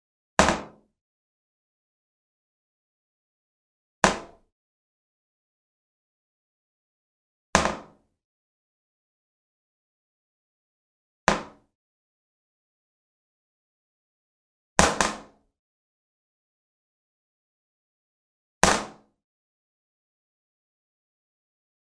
Toilet Lid Raise Up Sound
household
Toilet Lid Raise Up